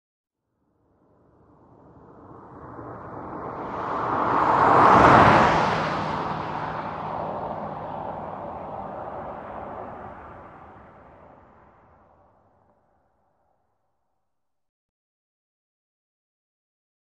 Corvette; By, Fast; Fast By With Heavy Sound Of Tires On Pavement. Medium Perspective. Sports Car, Auto.